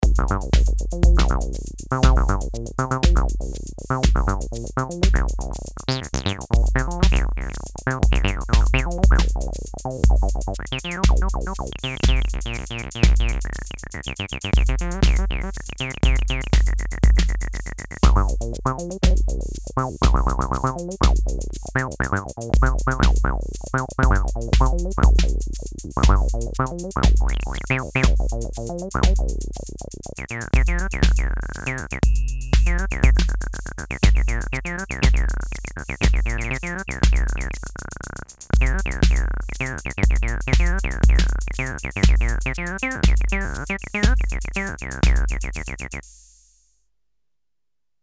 Tb-3 generates a monophonic voice and 4 kind of digital oscillators models: saw and square (modeled on tb303), leads, FX and bass.
another jam with 808